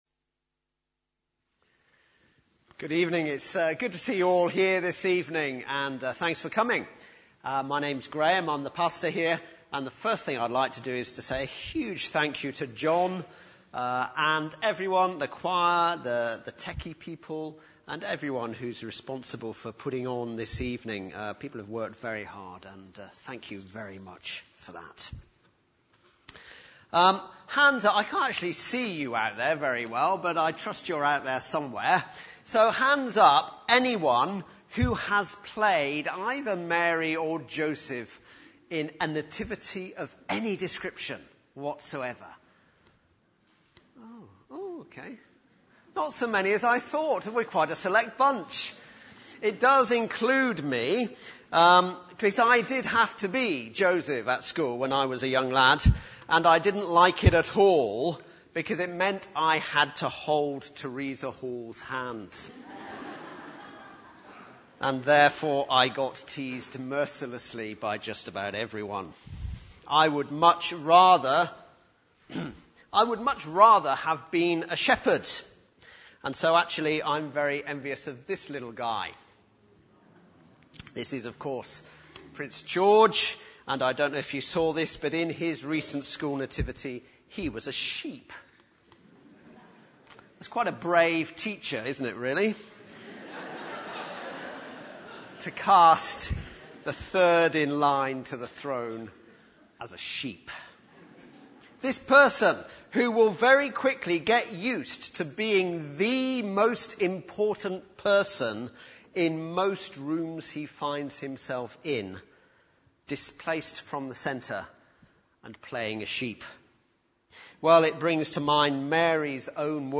Carol Service 2017